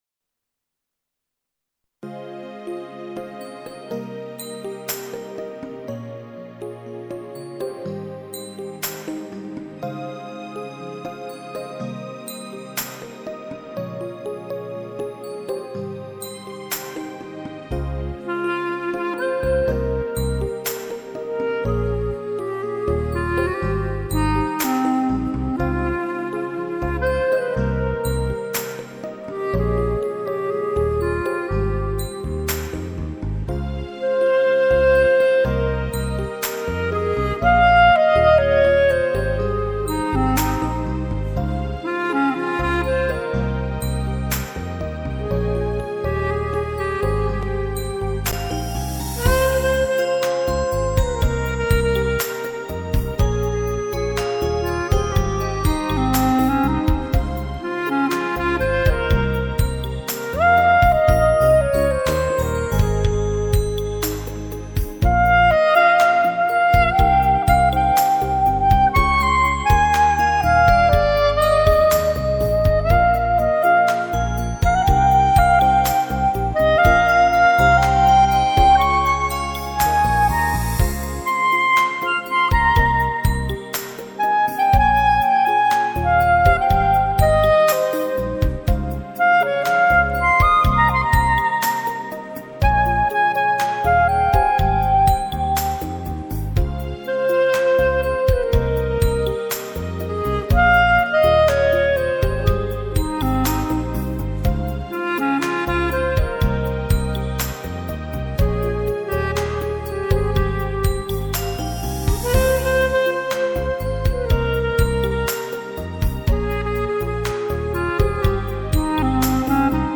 Adagio 请欣赏一首双簧管演奏的音乐名曲 06.